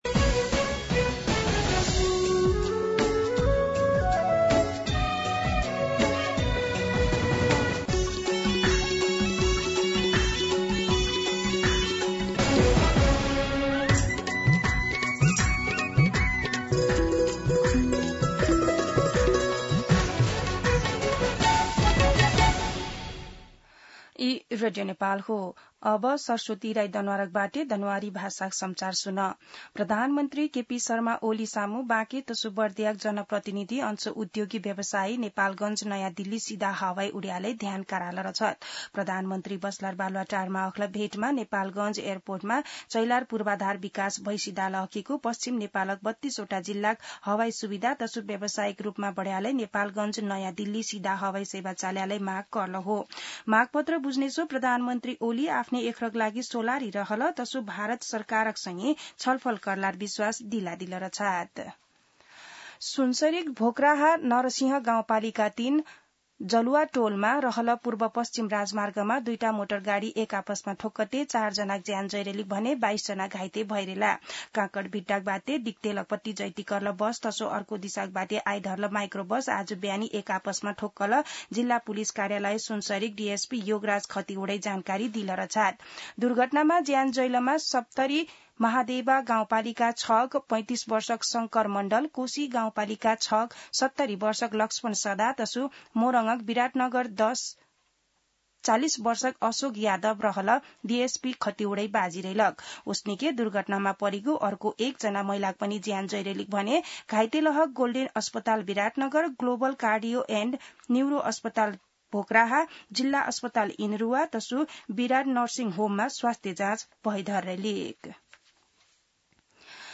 An online outlet of Nepal's national radio broadcaster
दनुवार भाषामा समाचार : २६ साउन , २०८२
Danuwar-News-3.mp3